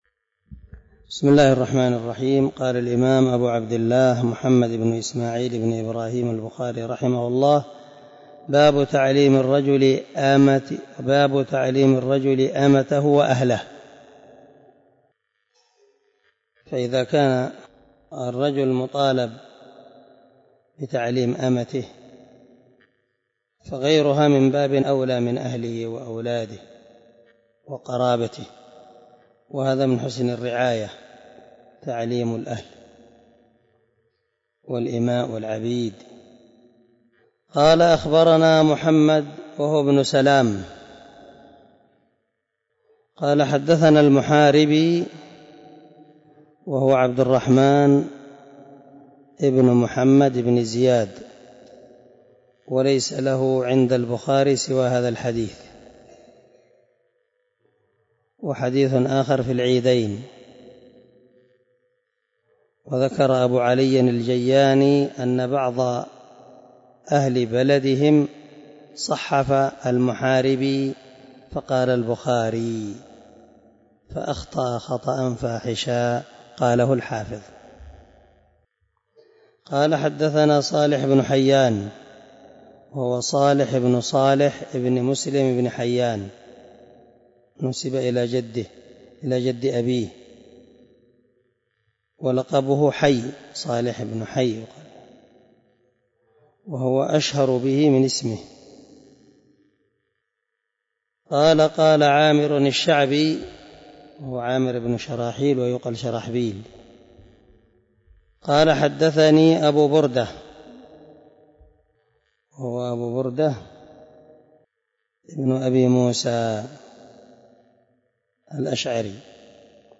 093الدرس 38 من شرح كتاب العلم حديث رقم ( 97 ) من صحيح البخاري